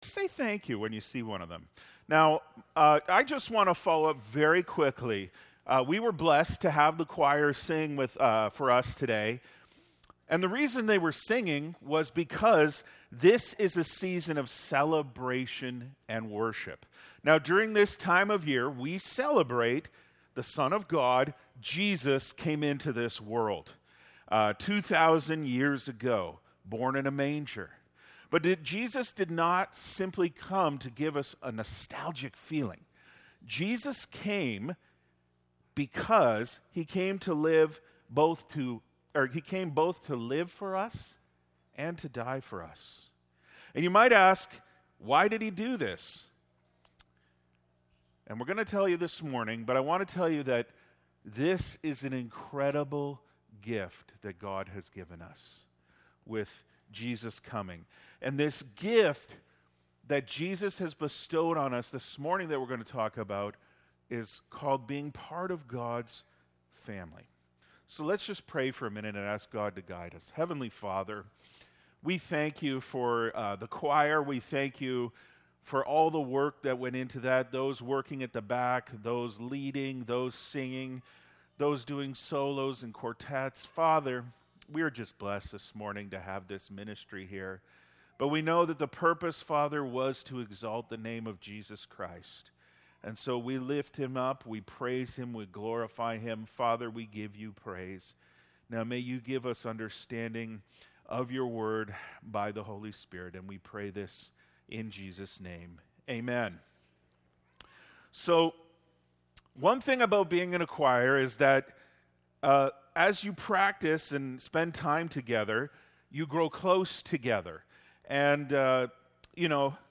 Sermons | Faith Baptist Church